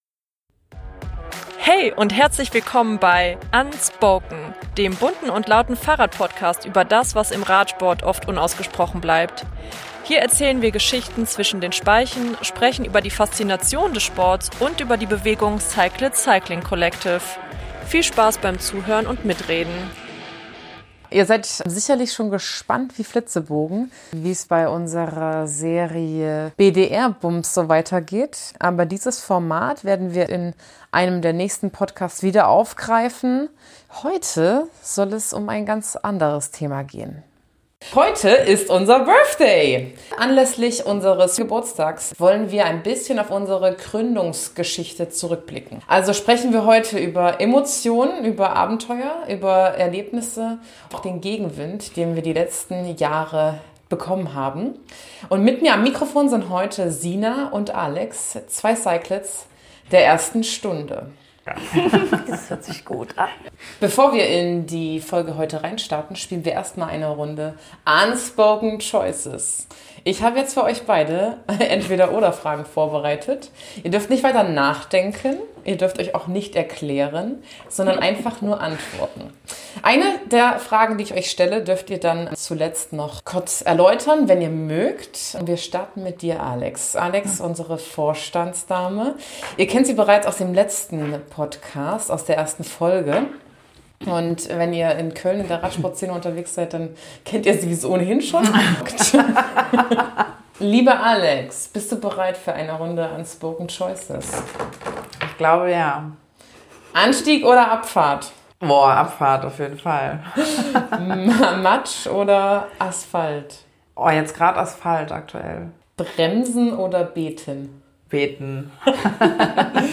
Beschreibung vor 2 Monaten Birthday Special Hinweis zur Folge: Diese Episode wurde unter unterschiedlichen technischen Bedingungen aufgenommen, daher ist der Sound stellenweise nicht podcast-perfekt, eher Grütze, der Inhalt dafür umso klarer. In dieser Folge von Unspoken feiern wir 5 Jahre e.V. und schauen zurück auf 7 wilde Jahre Community, Empowerment auf zwei Rädern, Gegenwind und den Widerstand gegen alte Strukturen.
Und sorry für’s schmatzen!